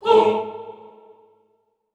Tm8_Chant58.WAV